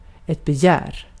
Ääntäminen
IPA : /lʌst/